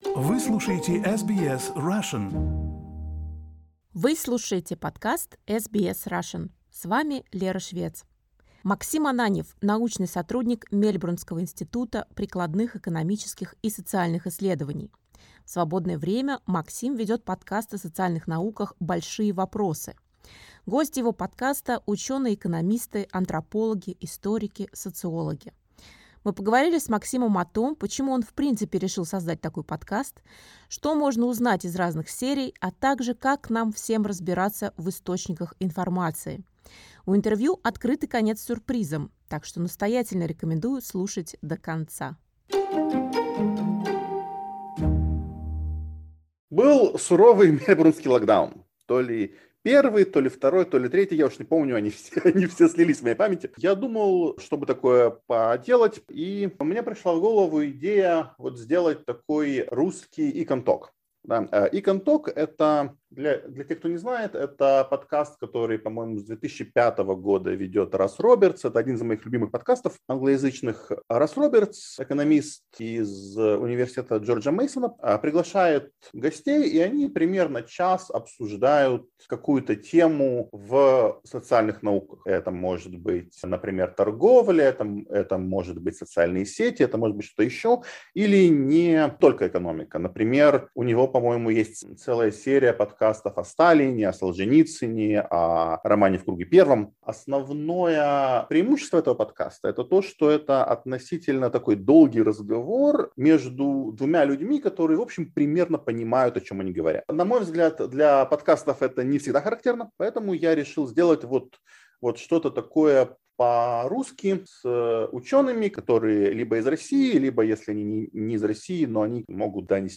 Экономист
интервью SBS Russian